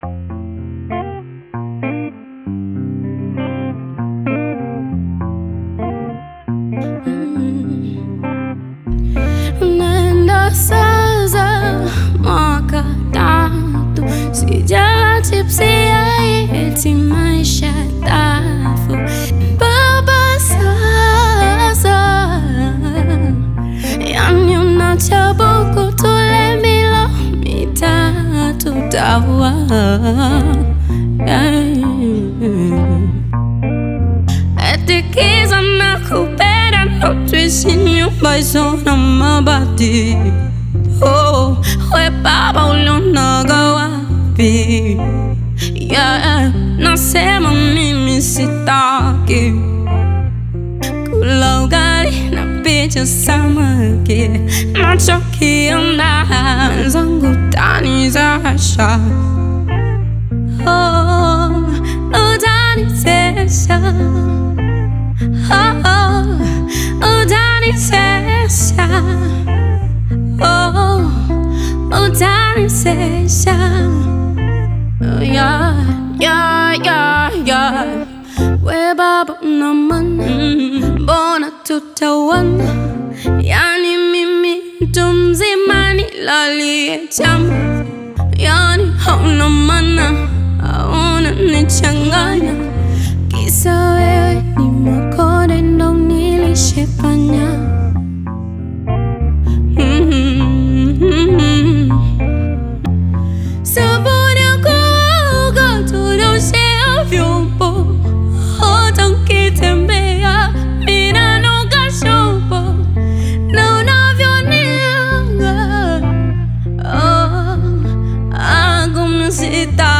Acoustic Performance Acoustic
Tanzanian Bongo Flava artist, singer and songwriter
Bongo Flava